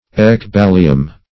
Ecballium \Ec*bal"li*um\, n. [NL., fr. Gr. ?. See Ecbole.]
ecballium.mp3